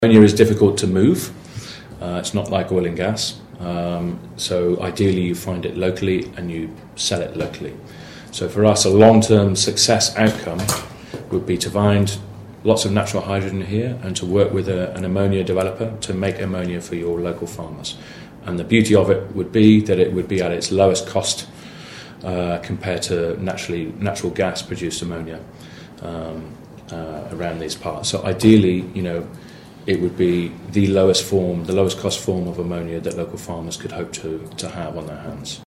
Representatives from Snowfox Discovery, a natural hydrogen exploration company, appeared before the Audubon County Board of Supervisors on Tuesday afternoon to outline their plans.